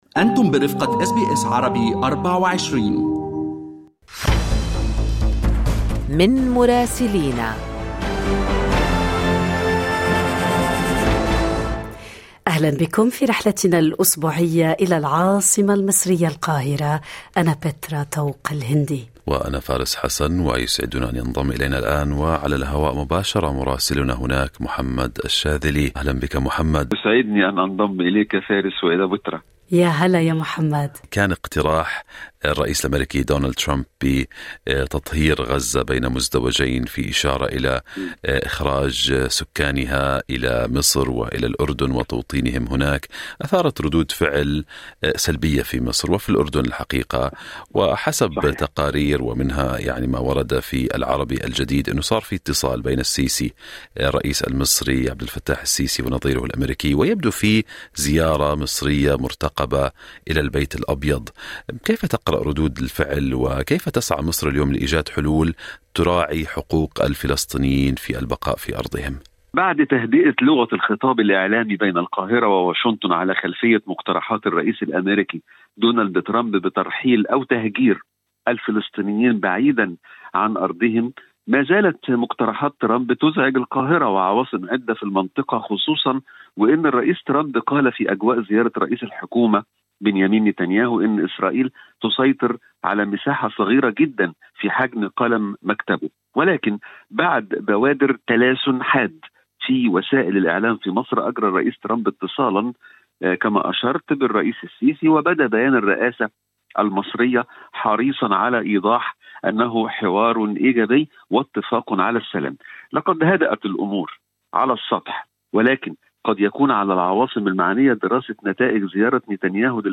أهم أخبار الدول العربية مع مراسلينا من لبنان ومصر والأراضي الفلسطينية والعراق والولايات المتحدة.